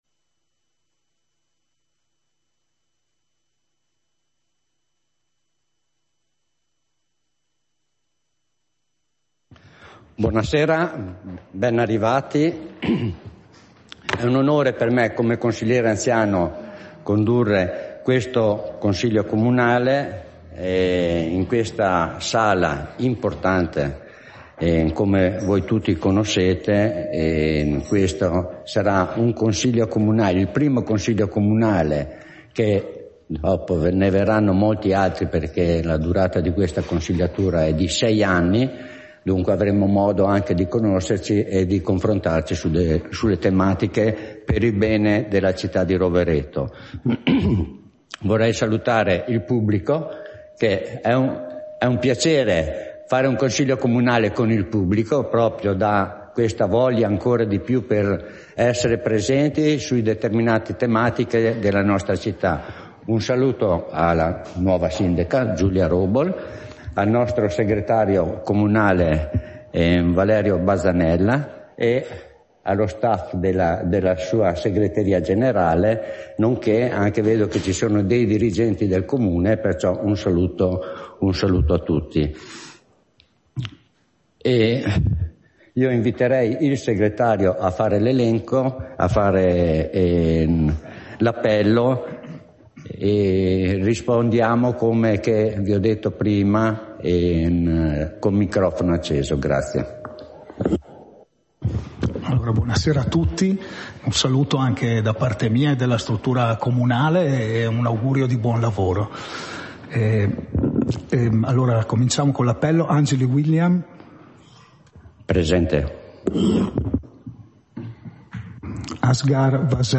Seduta del consiglio comunale - 26 giugno 2024